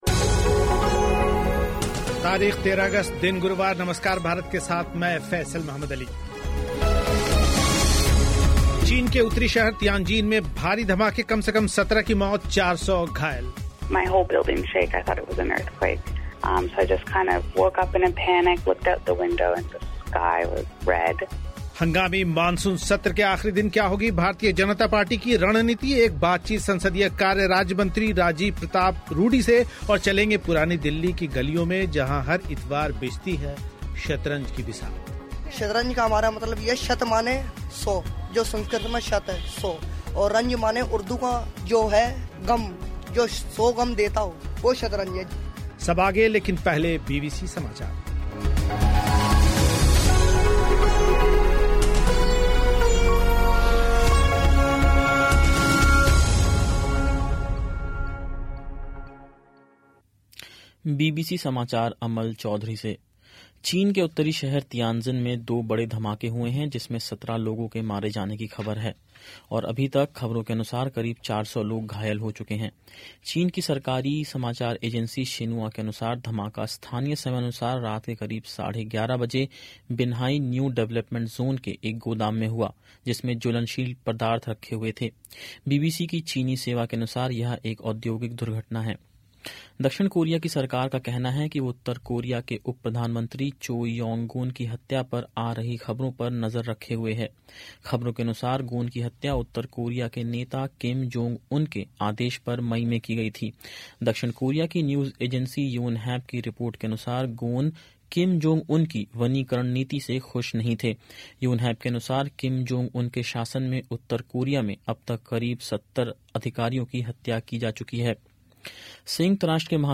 चीन के उत्तरी शहर तियांजिन में भारी धमाके, कम से कम 17 की मौत, 400 घायल, सुनें एक रिपोर्ट हंगामी मानसून सत्र के आख़िरी दिन क्या होगी भारतीय जनता पार्टी की रणनीति, एक बातचीत संसदीय कार्य राज्यमंत्री राजीव प्रताप रूडी से